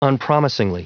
Prononciation du mot unpromisingly en anglais (fichier audio)
Prononciation du mot : unpromisingly